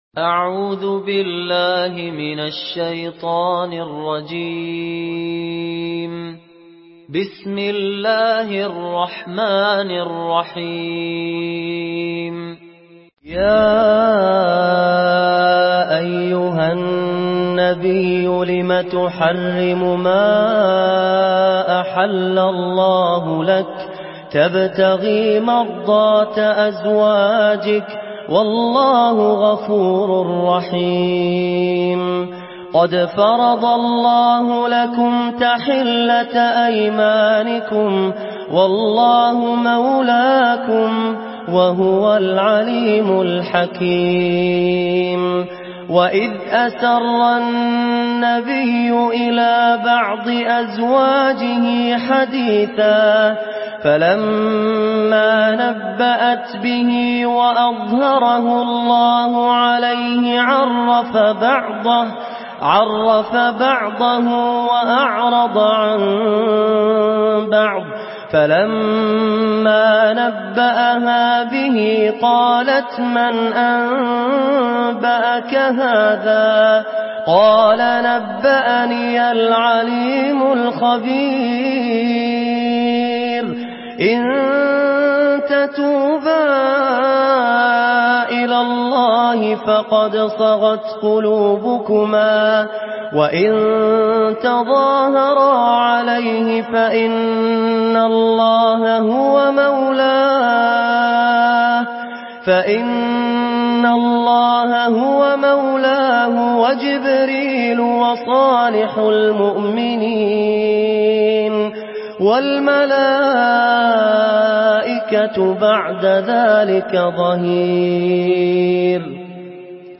سورة التحريم MP3 بصوت فهد الكندري برواية حفص
مرتل